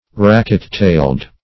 Search Result for " racket-tailed" : The Collaborative International Dictionary of English v.0.48: Racket-tailed \Rack"et-tailed`\ (r[a^]k"[e^]t-t[=a]ld`), a. (Zool.) Having long and spatulate, or racket-shaped, tail feathers.
racket-tailed.mp3